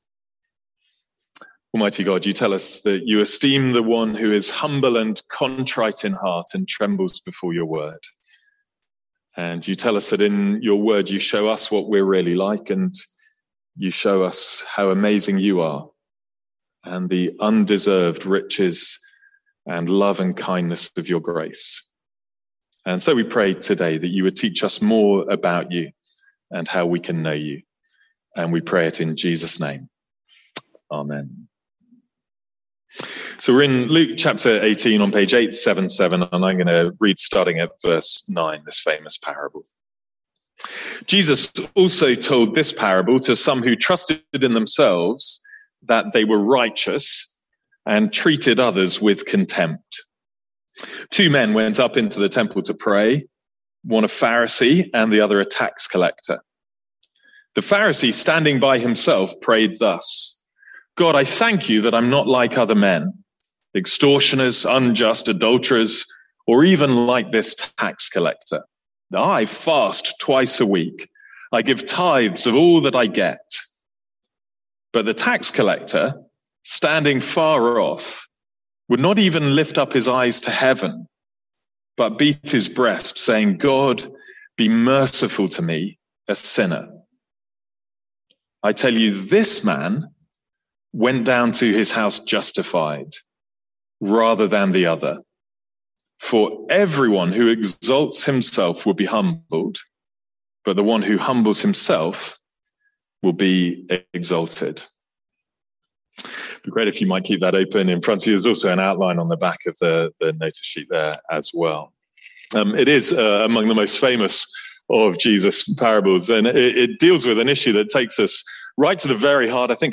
Sermons | St Andrews Free Church
From our A Passion for Life Sunday Services.